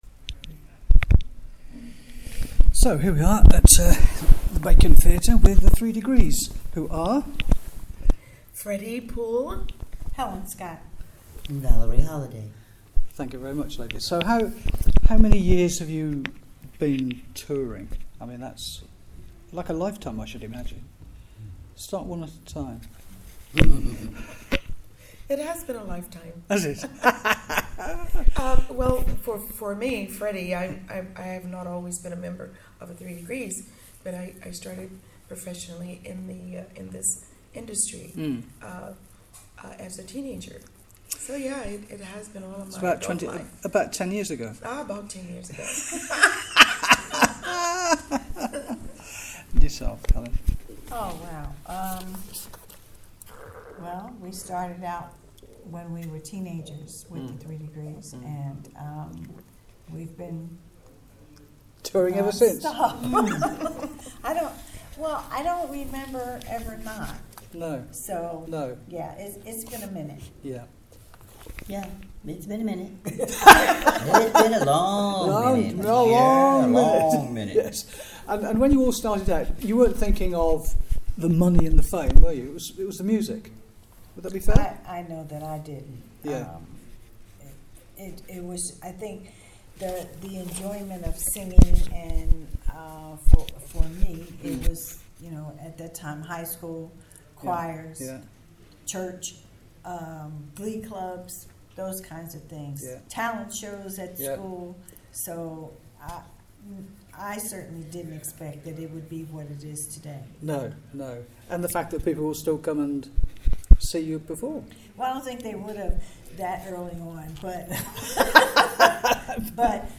Valerie Holiday ,Helen Scott and Freddie Pool(above) kindly spoke with NCCR “In The Cooler” before their show at the Bacon Theatre,Cheltenham on 23rd September 2018.